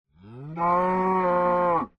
mob / cow / say3.ogg
should be correct audio levels.